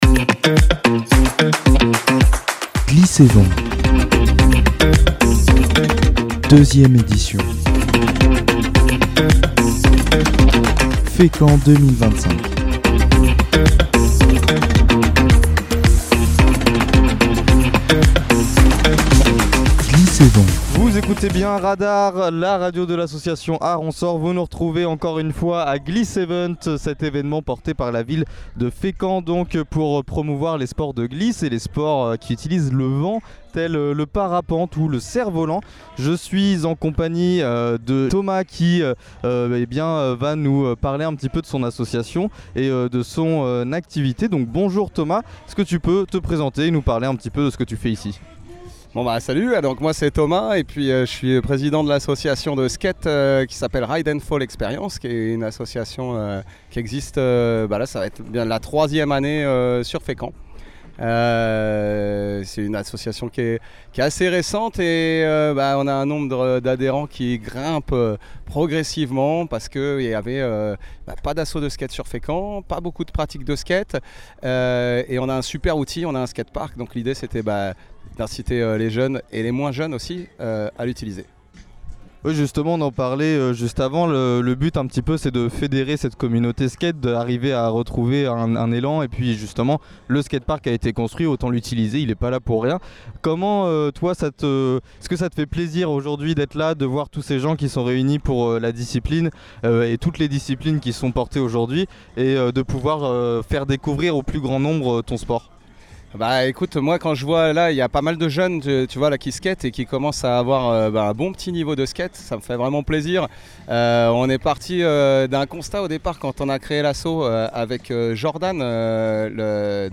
Le studio mobil est une caravane entièrement équipée pour produire des émissions couvrant différents événements. On y reçoit les acteurs et participants de ces manifestations pour les interviewer en direct (quand les connexions sont possibles) ou en conditions de direct, ce qui donne de nombreux podcasts à retrouver ici.
Studio mobile fécamp bmx glisse sport skate voile vent gliss vélo trottinette gliss & vent